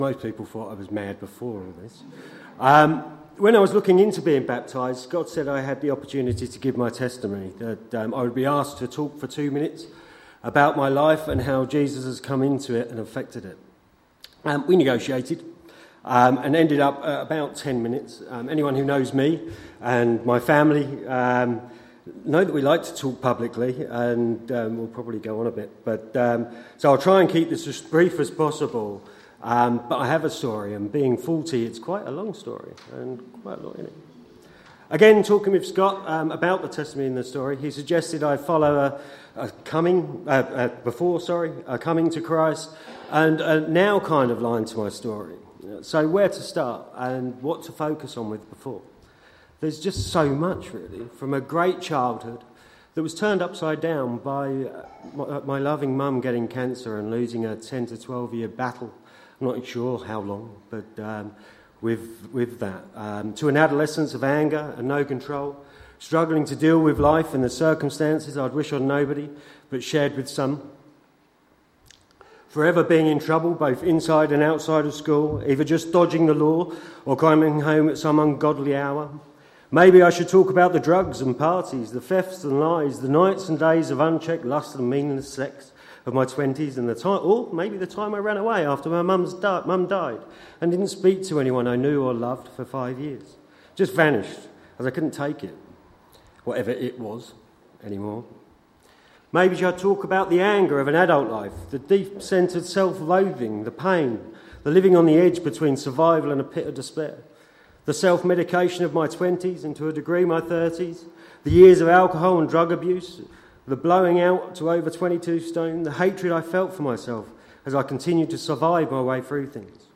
A message from the series "Colossians."